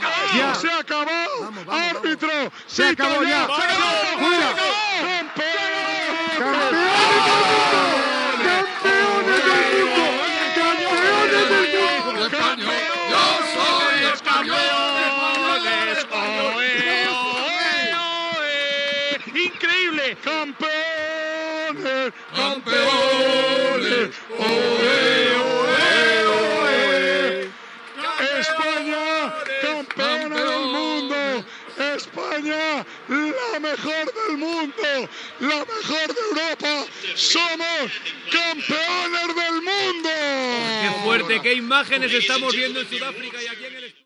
Transmissió del partit de la final de la Copa del Món de Futbol masculí 2010, des de Sud-àfrica.
Narració del final del partit en el qual la selecció espanyola de futbol masculí guanya la Copa del Món de futbol.
Esportiu